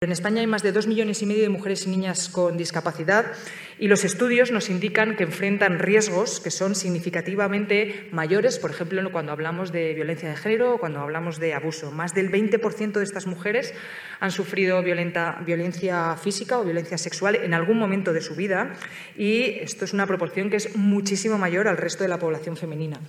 Así lo manifestó en el diálogo sobre ‘Salud, Género y Discapacidad’, organizado por la agencia de noticias Servimedia y Estudio de Comunicación, y que tuvo lugar en el Hub de Por Talento Digital de Fundación ONCE.